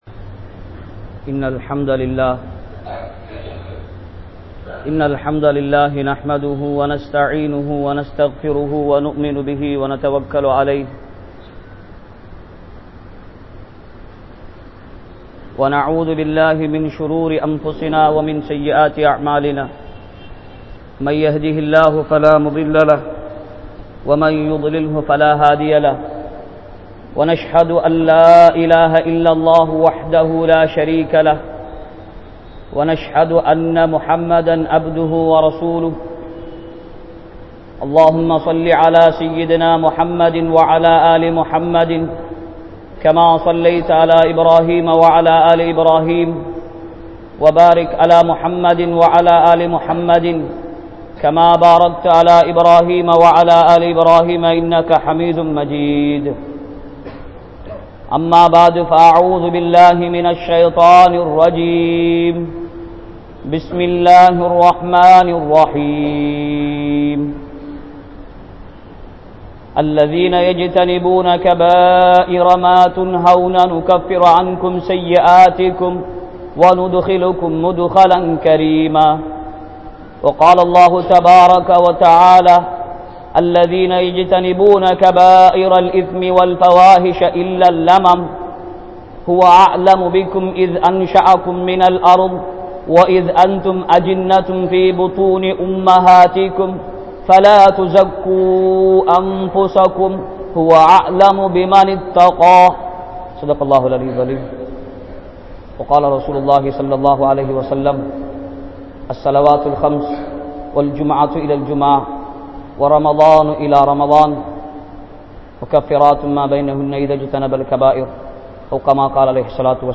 Paavaththai Vittu Vidungal (பாவத்தை விட்டு விடுங்கள்) | Audio Bayans | All Ceylon Muslim Youth Community | Addalaichenai
Grand Jumua Masjith